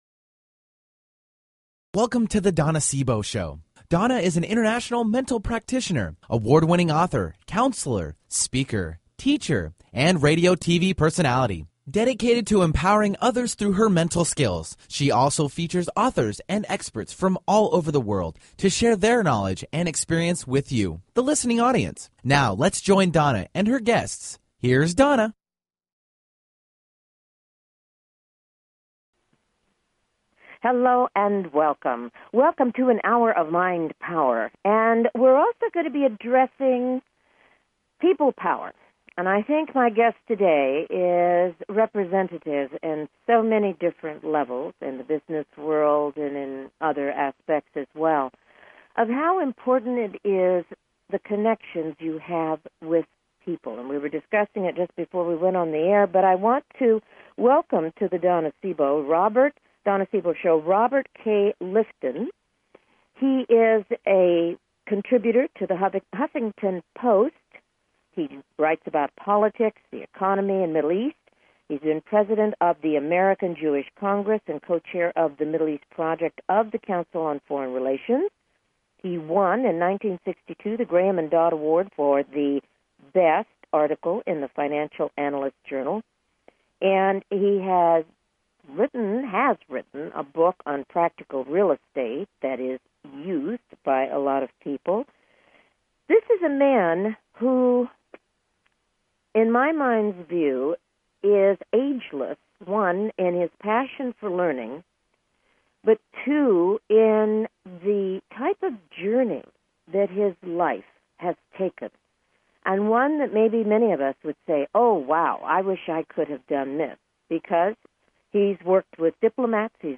Her interviews embody a golden voice that shines with passion, purpose, sincerity and humor.